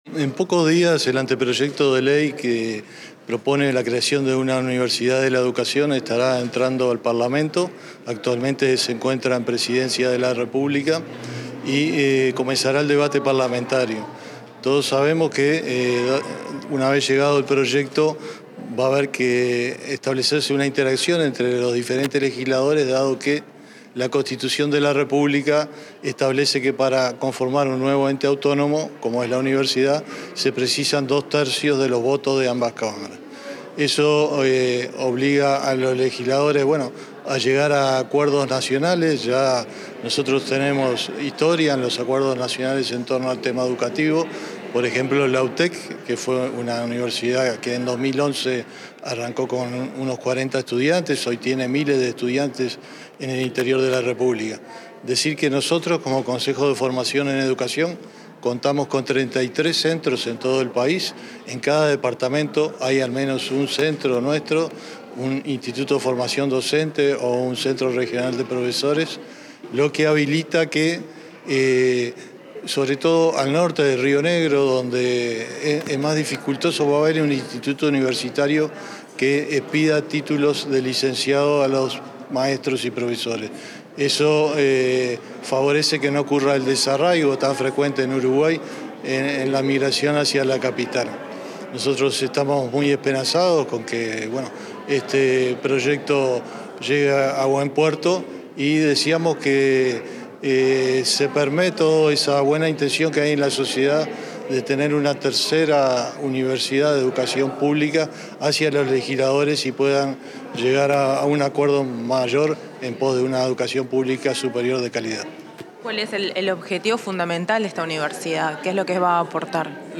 Declaraciones del presidente del CFE, Walter Fernández